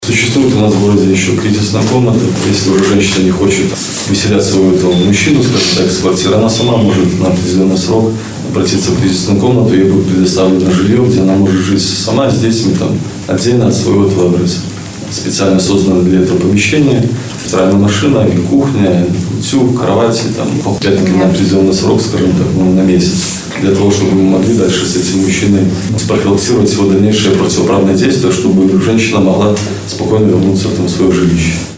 В Барановичском ГОВД состоялся брифинг на тему «Противодействие насилию в быту» (+ audio)